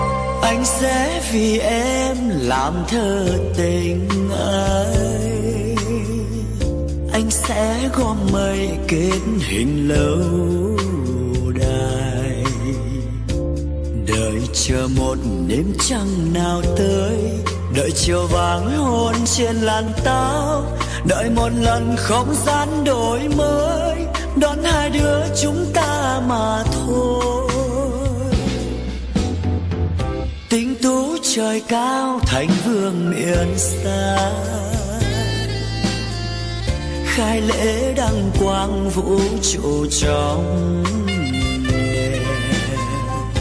Nhạc Bolero